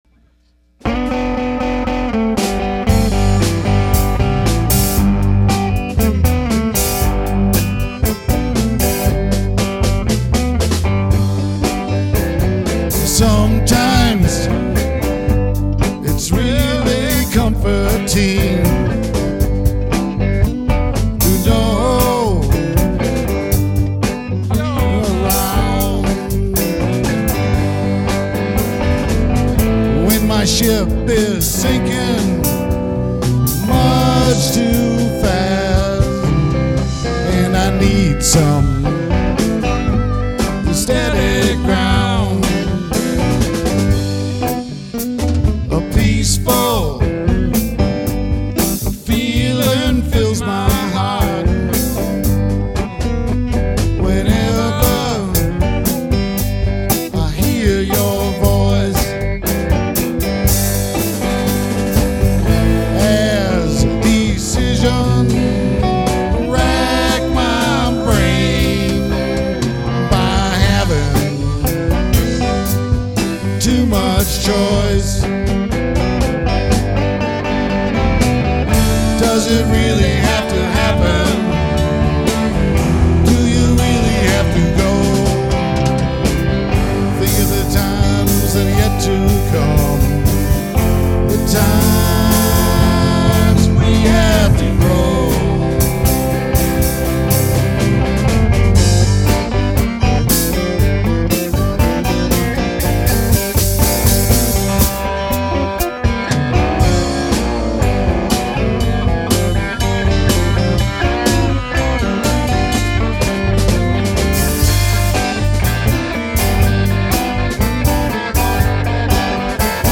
drums
harmonica
third guitar